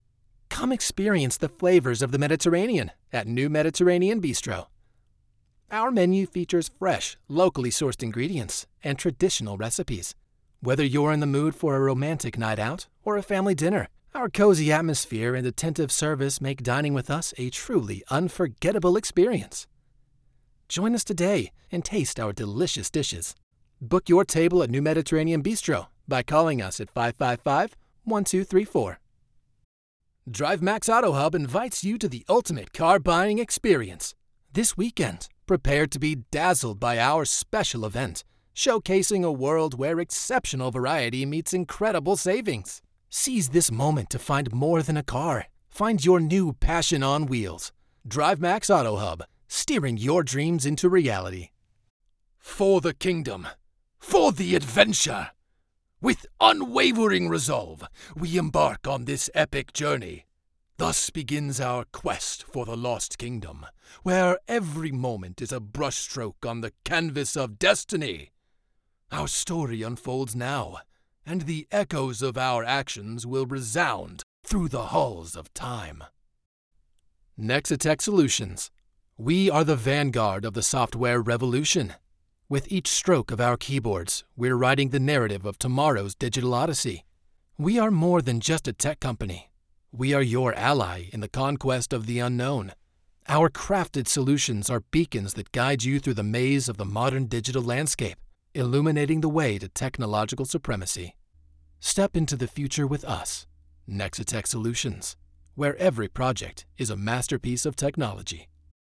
Commercial Sampler